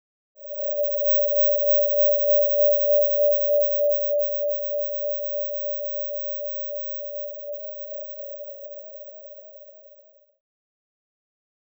ghostEnemy.wav